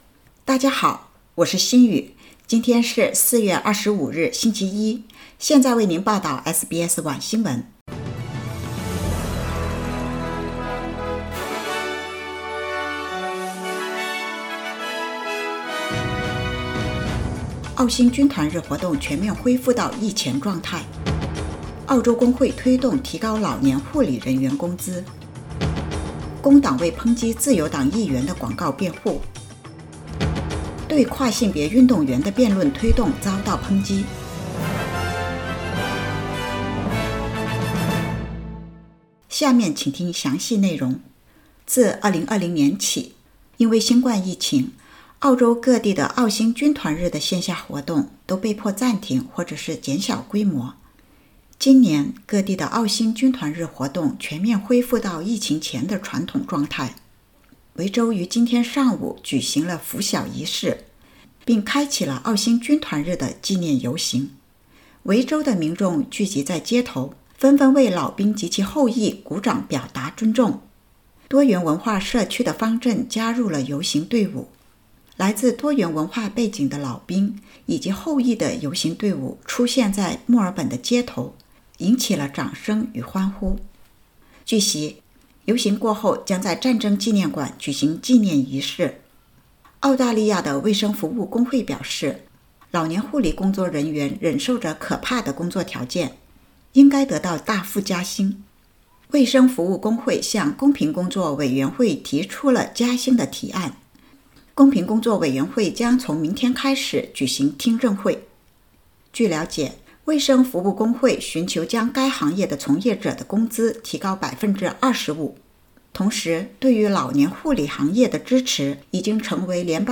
SBS晚新闻（2022年4月25日）